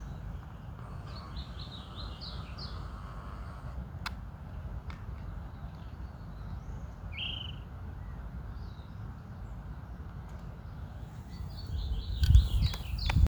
Burlisto Pico Negro (Myiarchus ferox)
Nombre en inglés: Short-crested Flycatcher
Localidad o área protegida: Reserva Privada San Sebastián de la Selva
Condición: Silvestre
Certeza: Fotografiada, Vocalización Grabada
Burlisto-pico-negro_1.mp3